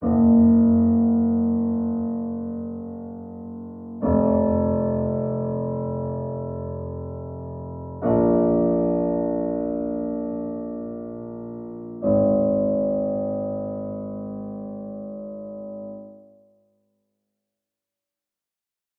AV_Peacemaker_Piano_60bpm_Bbmin.wav